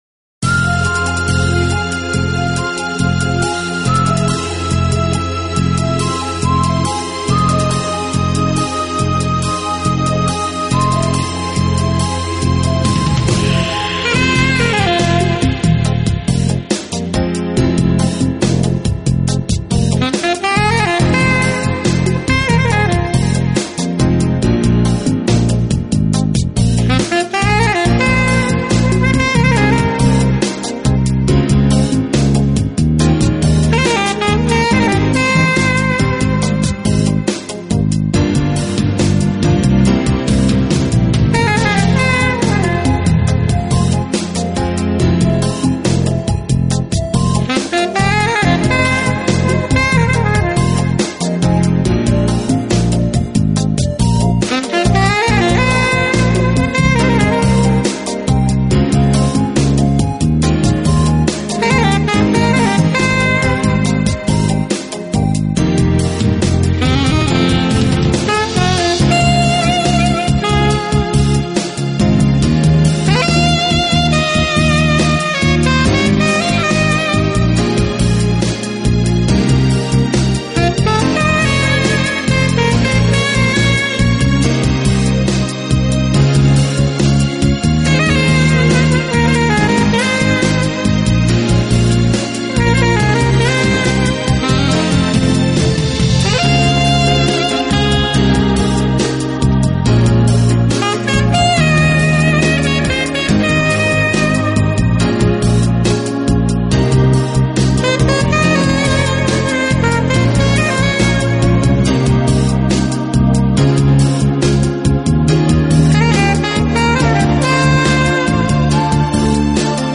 Genero/Genre: Smoth Jazz/Orchestral Pop
用萨克管演奏情调爵士乐，上世纪六十年代开始很走红，到上个世纪七十年代达到顶峰，